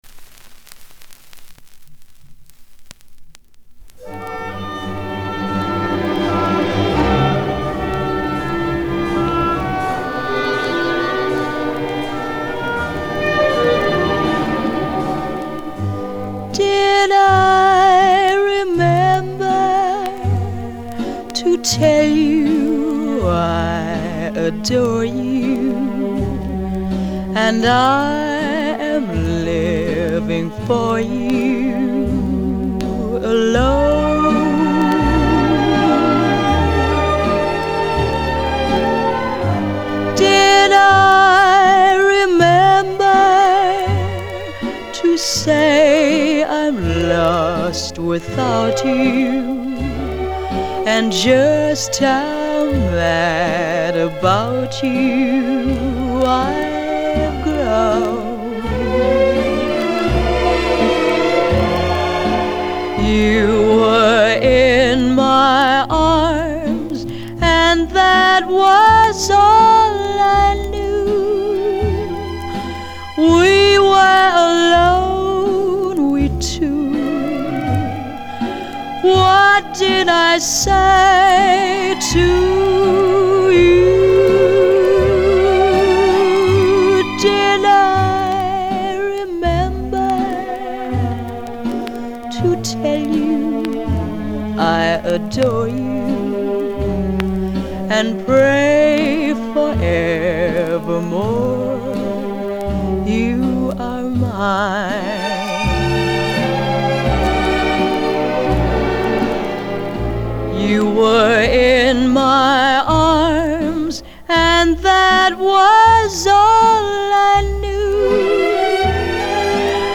형식:레코드판, LP, Album, Mono
장르:Pop 1955.